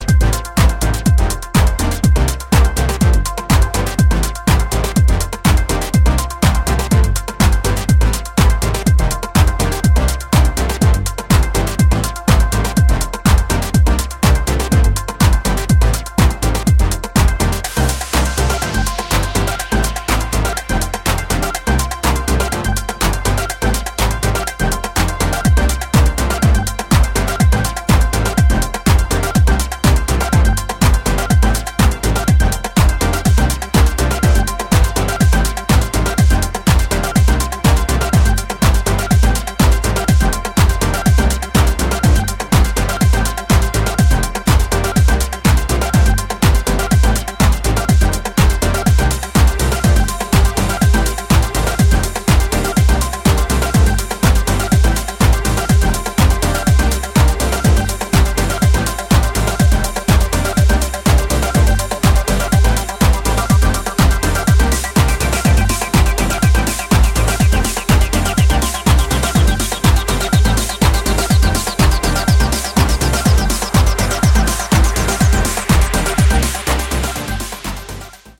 クラシカルでオーセンティックな魅力を放つ、ソリッドなディープ・ハウス群を確かな手腕で展開しています。